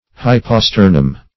[1913 Webster] The Collaborative International Dictionary of English v.0.48: Hyposternum \Hy`po*ster"num\, n.; pl.